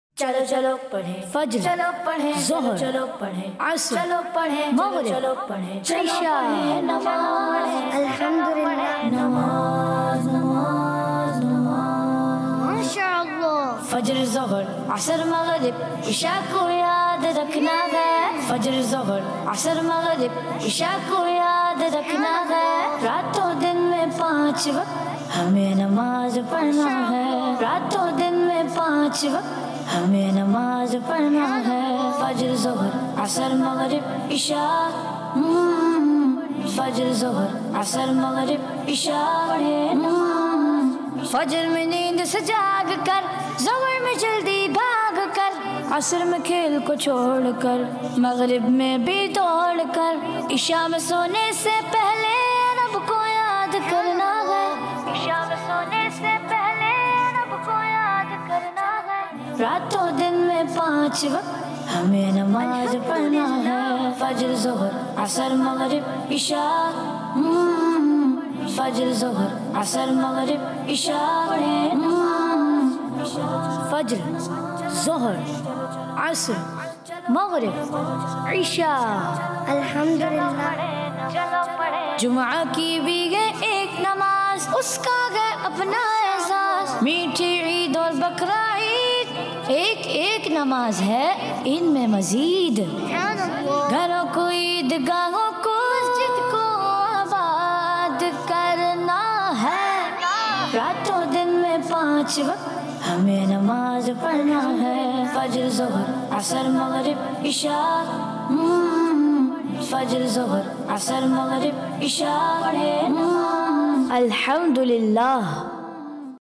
Vocalist